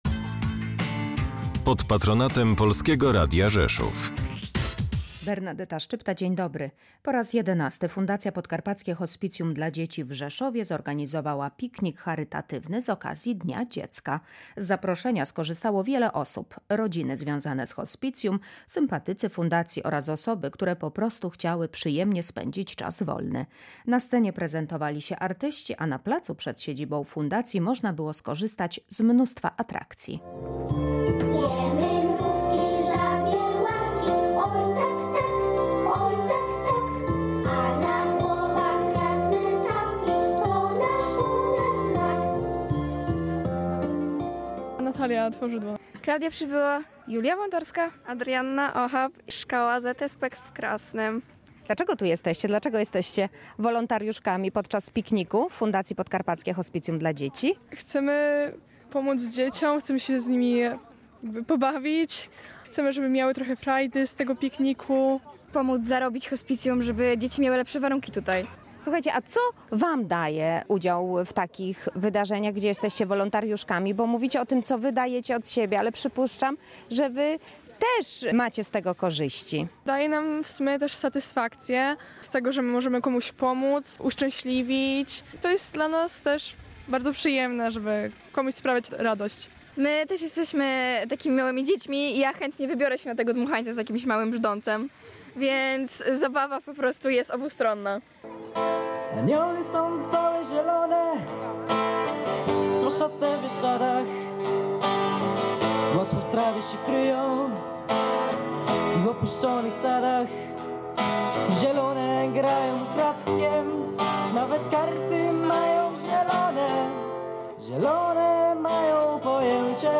Tak było podczas Pikniku Charytatywnego Fundacji Podkarpackie Hospicjum dla Dzieci w Rzeszowie (zdjęcia)